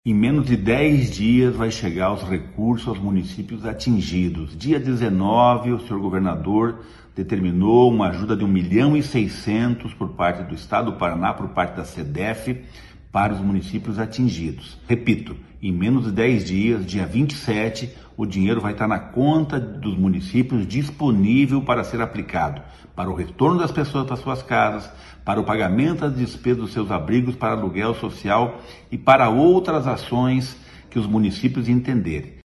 Sonora do secretário do Desenvolvimento Social e Família, Rogério Carboni, sobre os novos recursos financeiros para apoio a desabrigados começarem a ser depositados nesta sexta